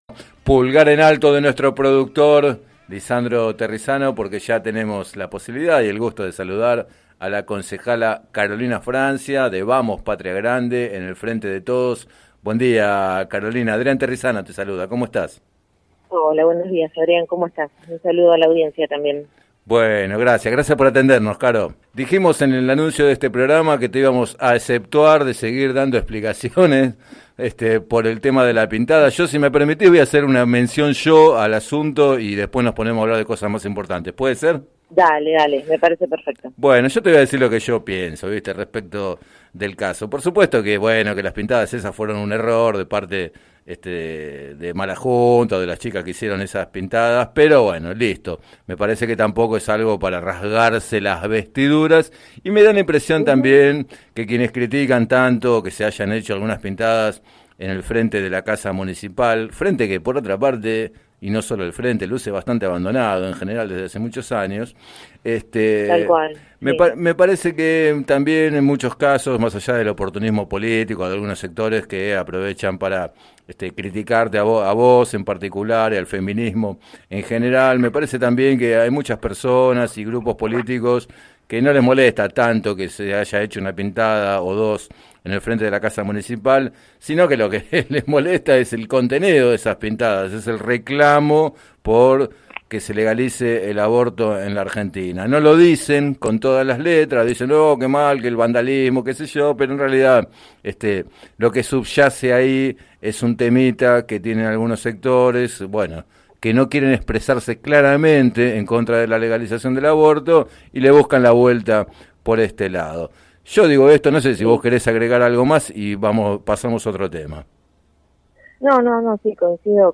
En declaraciones al programa Planeta Terri de Radio Líder 97.7, Francia consideró que “estamos mejor preparadas para el debate que en 2018 porque hicimos un proceso desde los movimientos feministas y organizaciones de la sociedad”.